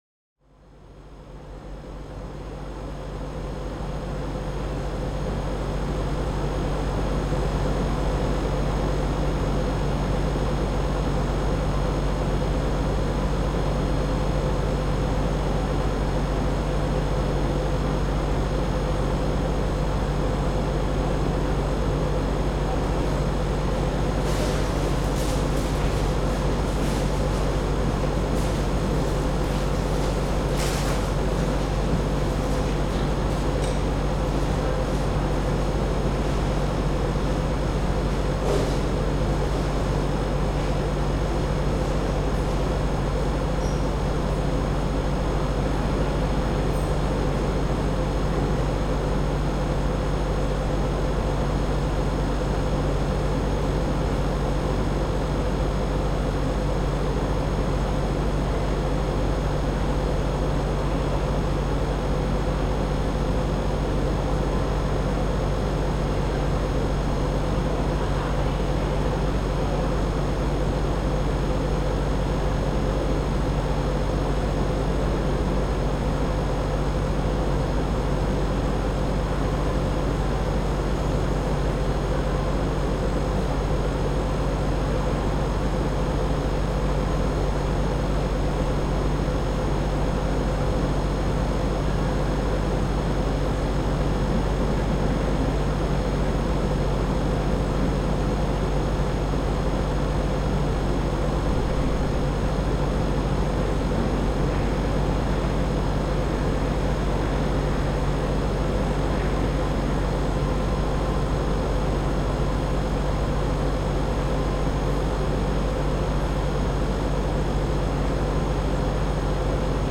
AMB_Scene01_Ambience_RS.ogg